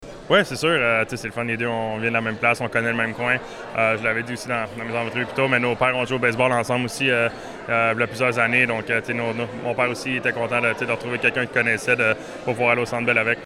Présent lors du dévoilement de la campagne de financement d’Entraide Bécancour jeudi, le cerbère n’a pas hésité à faire l’éloge du nouvel attaquant du Tricolore.